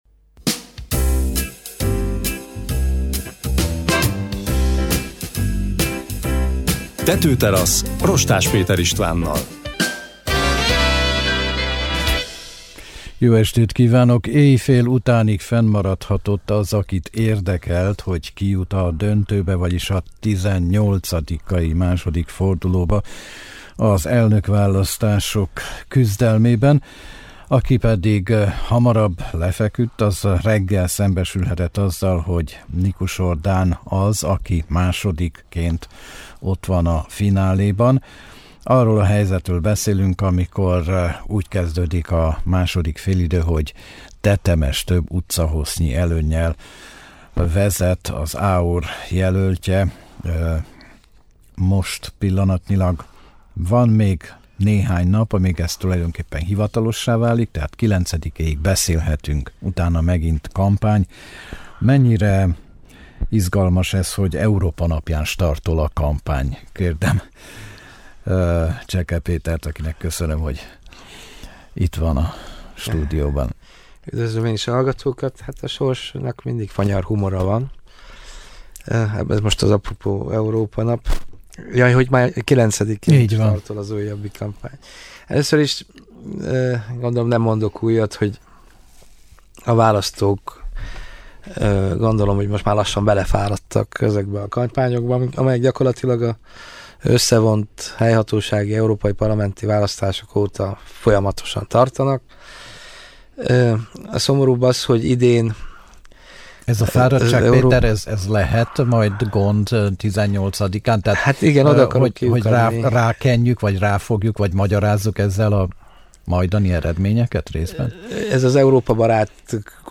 Százalékokban nem, de hipotézisekben bővelkedő beszélgetés arról, hogy miért aggasztó, de nem reménytelen belpolitikai helyzetünk az államfőválasztás első fordulója után.